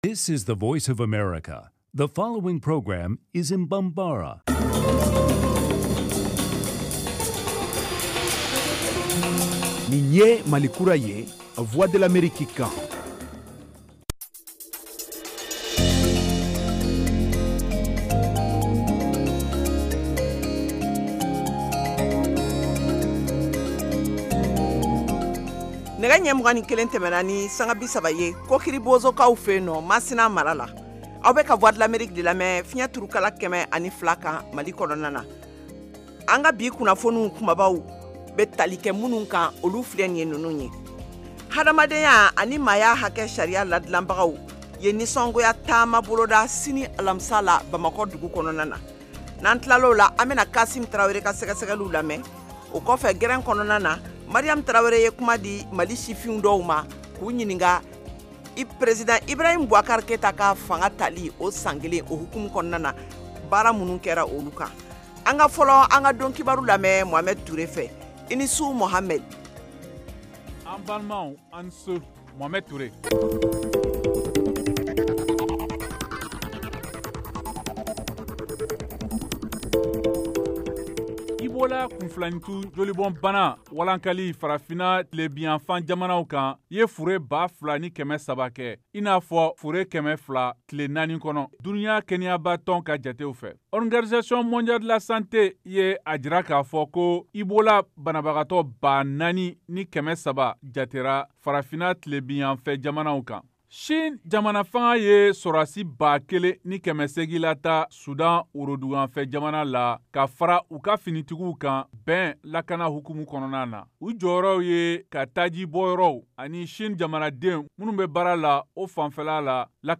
Emission quotidienne en langue bambara
en direct de Washington